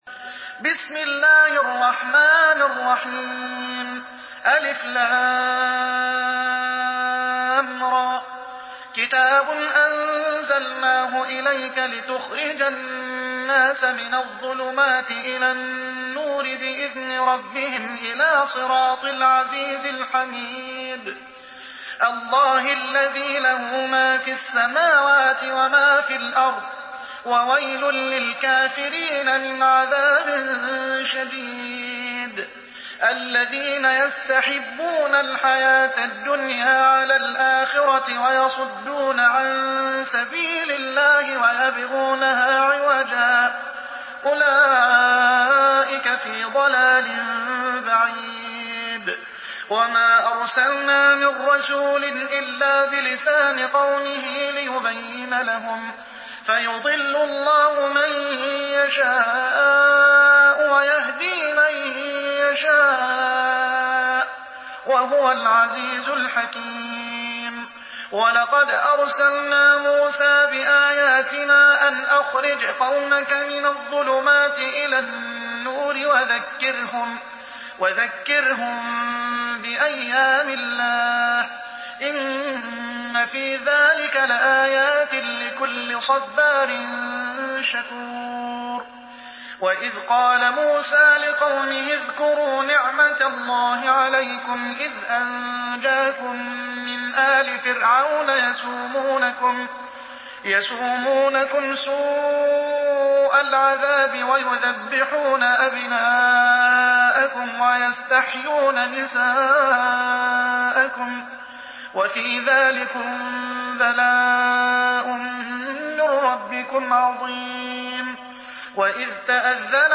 سورة إبراهيم MP3 بصوت محمد حسان برواية حفص
مرتل